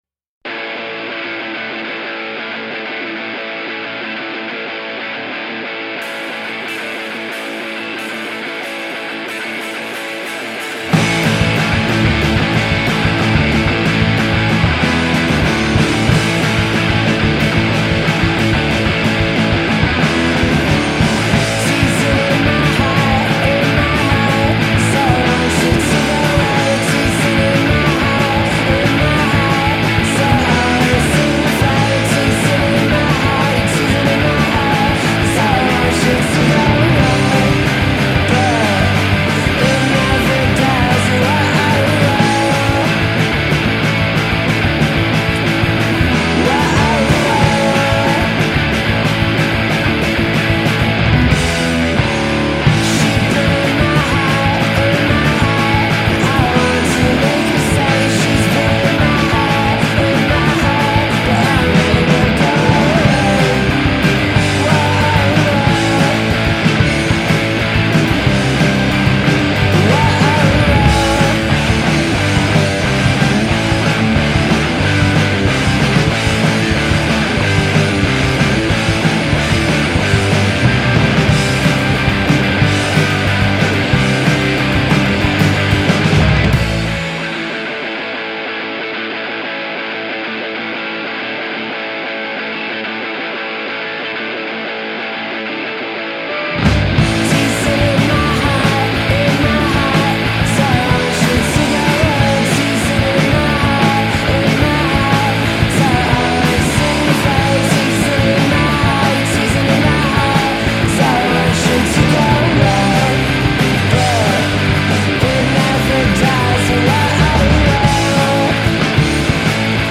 sun-inflected guitar pop
a dense wall of guitar fuzz